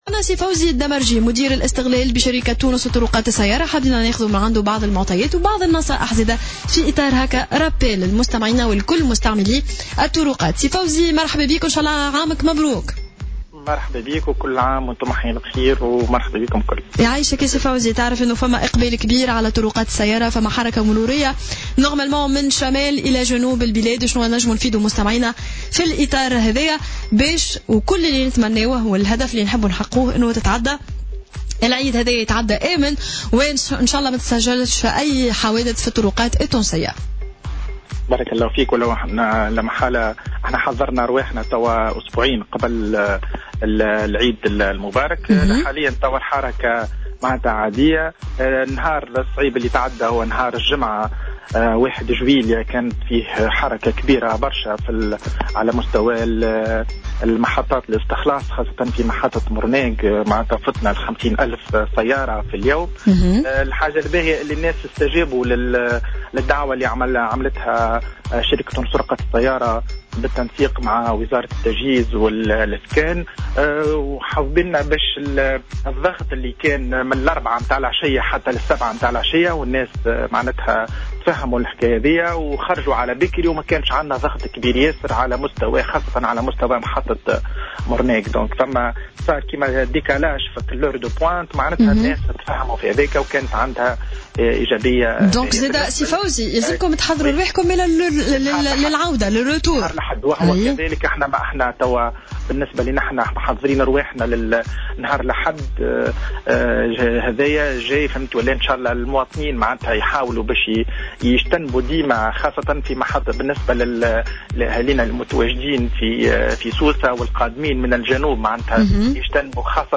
في مداخلة له اليوم على "الجوهرة أف أم"